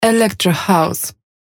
Категория: Скачать Фразы и Произношения